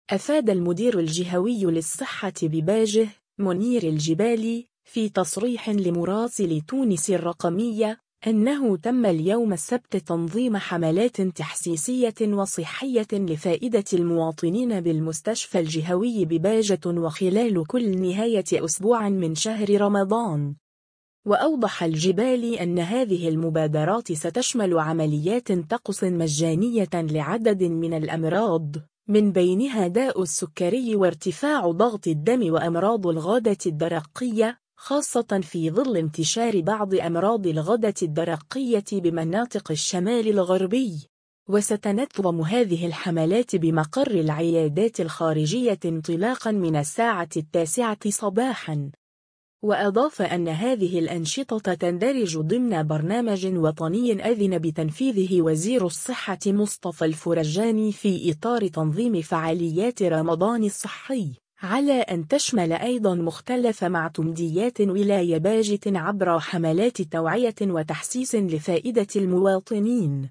أفاد المدير الجهوي للصحة بباجة، منير الجبالي، في تصريح لمراسل تونس الرقمية، أنه تم اليوم السبت تنظيم حملات تحسيسية وصحية لفائدة المواطنين بالمستشفى الجهوي بباجة وخلال كل نهاية أسبوع من شهر رمضان.